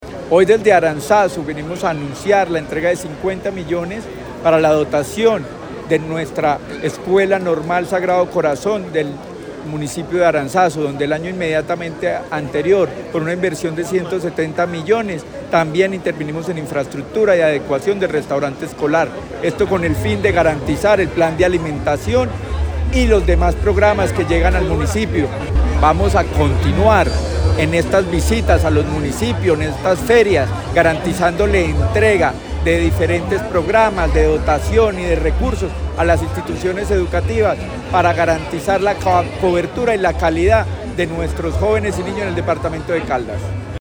Luis Herney Vargas Barrera, secretario de Educación de Caldas
Secretario-de-Educacion-Luis-Herney-Vargas-entrega-Aranzazu.mp3